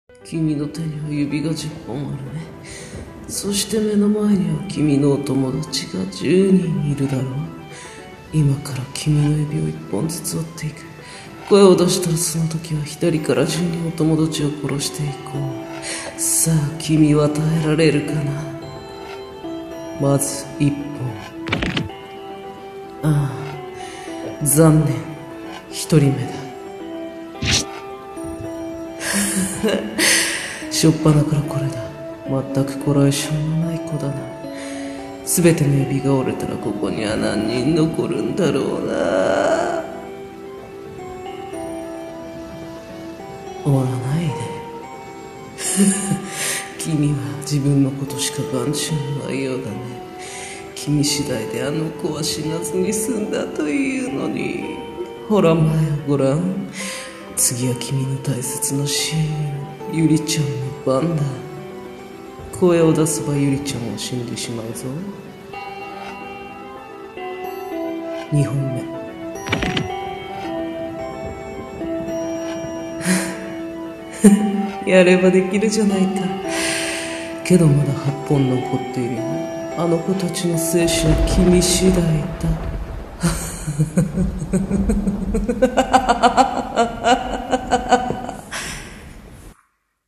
指折り殺人鬼【ホラー声劇】